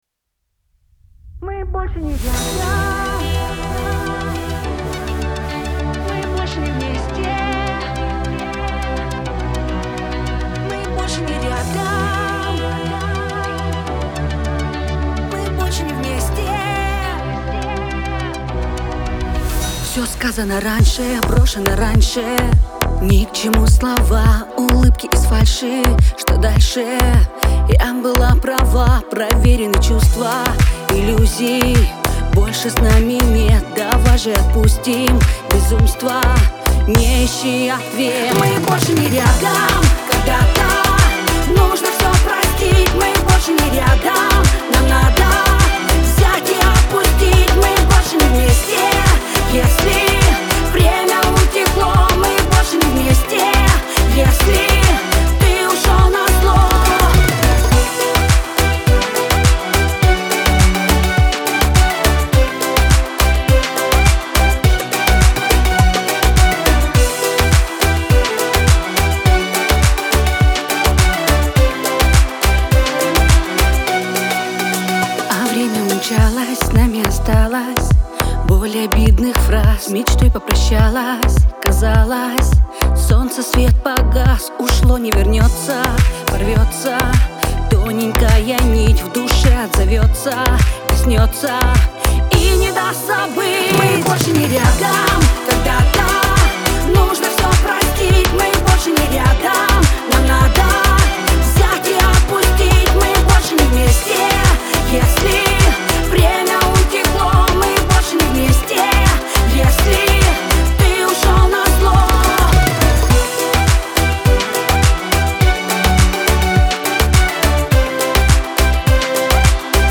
диско , pop
грусть